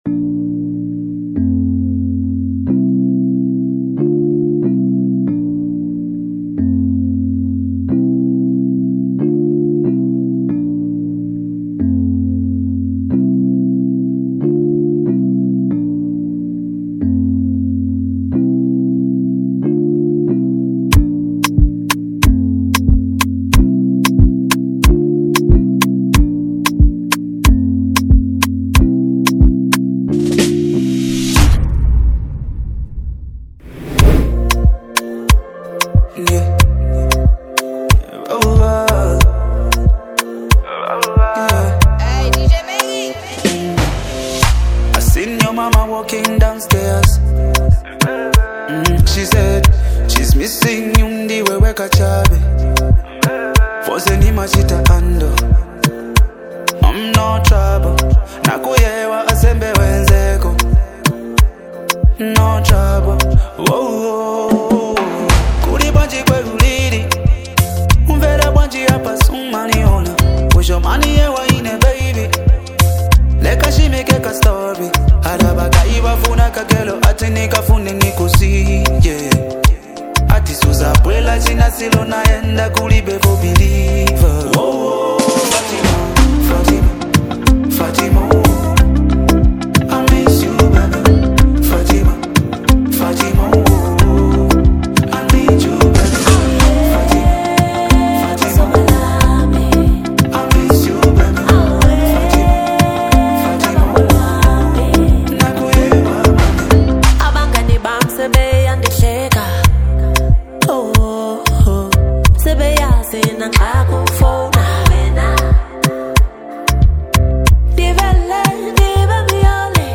Afrobeat and R&B-infused music
Afro-soul sound